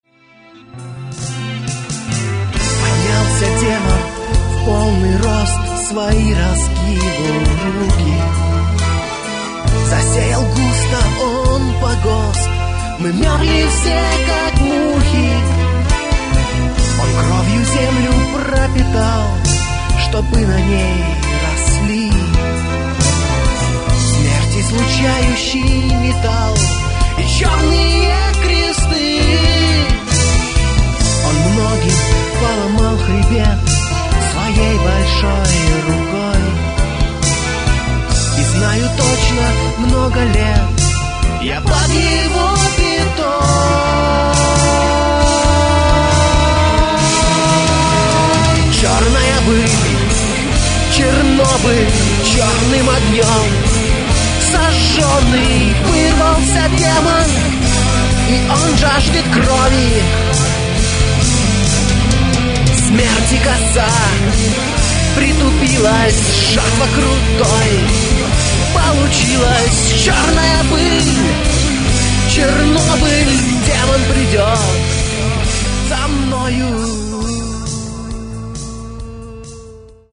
Гитары, бас, клавиши, перкуссия, вокал
фрагмент (472 k) - mono, 48 kbps, 44 kHz